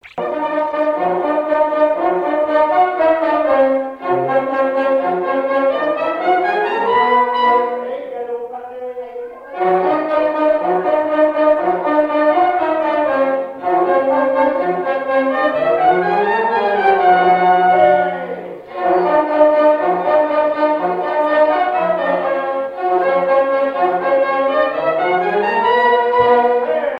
Quadrille - Le galop
danse : quadrille : galop
Pièce musicale inédite